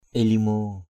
/i-li-mo:/ (d.) văn hóa = culture. culture, educated. bathak canar ilimo bEK cqR il{_m% trung tâm văn hóa = centre culturel. cultural center. bhap ilimo BP il{_m% văn hóa dân...